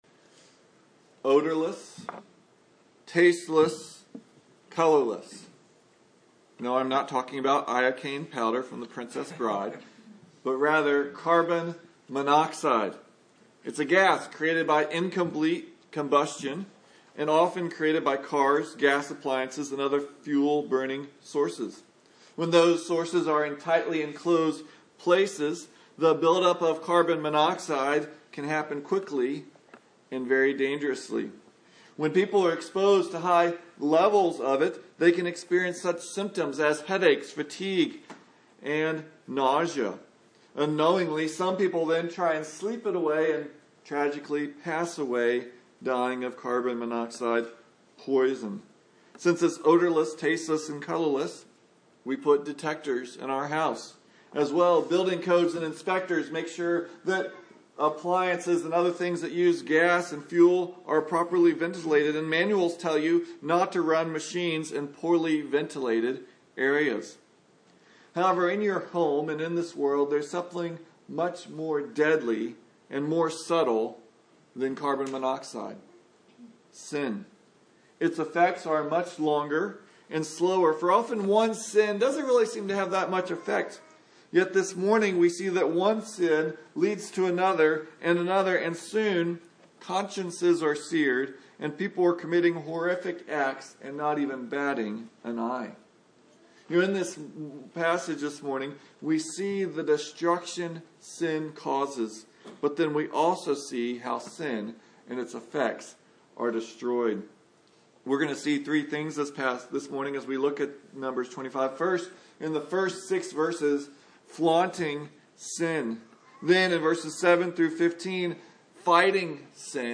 Numbers 25 Service Type: Sunday Morning Many in the nation of Israel die in a Balaam-induced temptation to the men of Israel. Sexual temptation leads to sexual sin which leads to spiritual adultery and idolatry, and only by God’s mercy in stirring up Phinehas is Israel delivered.